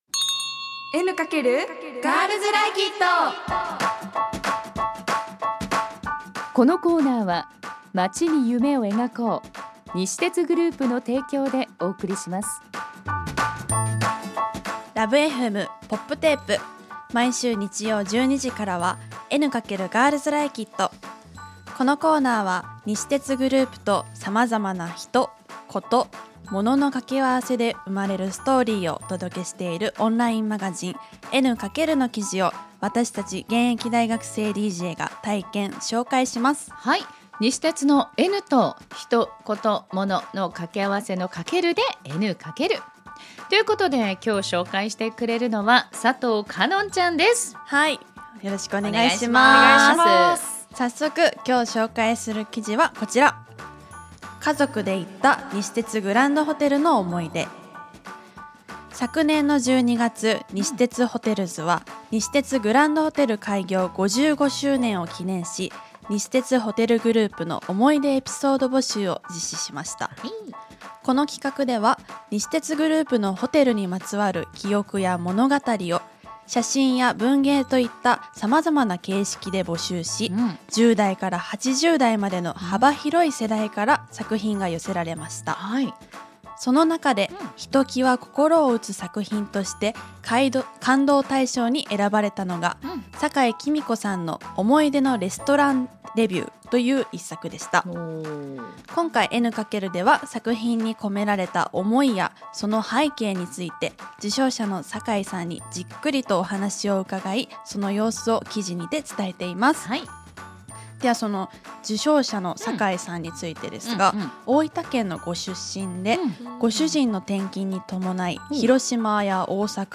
女子大生DJが「N× エヌカケル」から気になる話題をピックアップ！